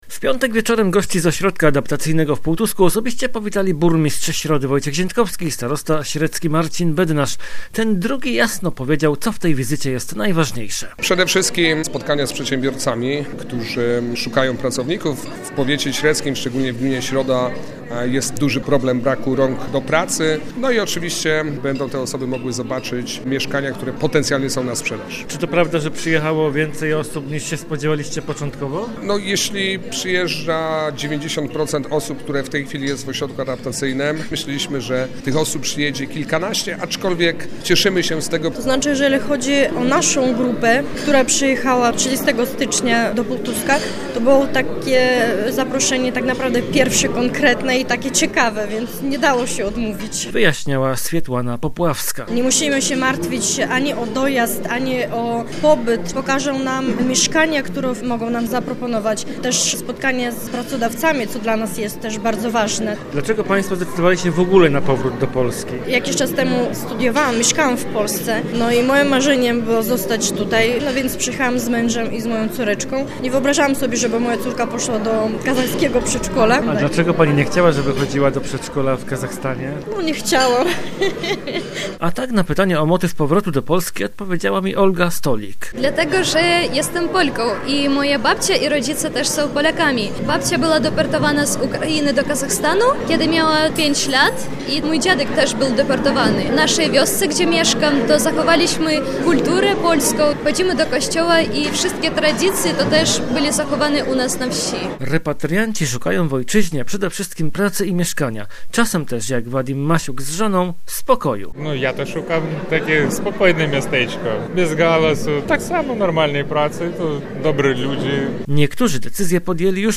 Czy rodaków udało się przekonać do przeprowadzki do Wielkopolski?